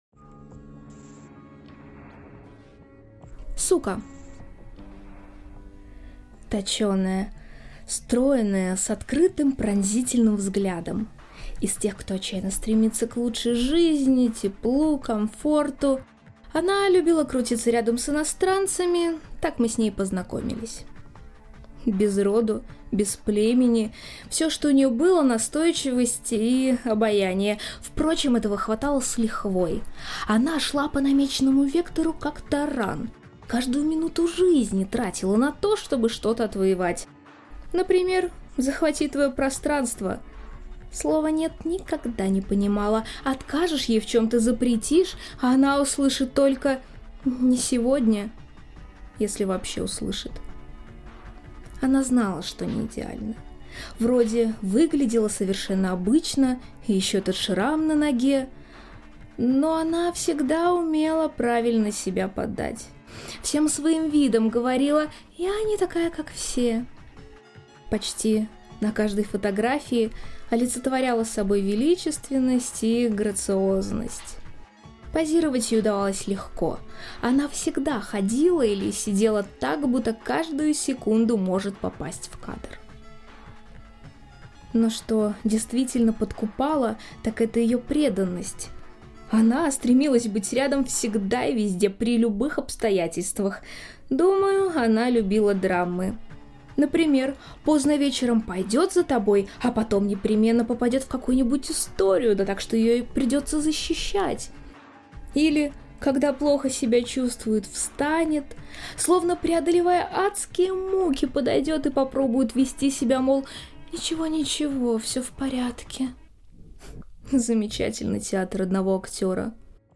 Аудиокнига Люди любят психов | Библиотека аудиокниг